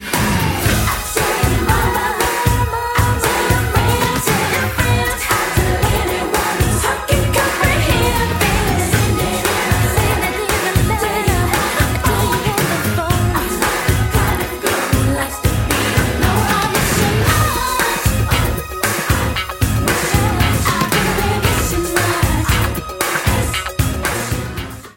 Soundtrack.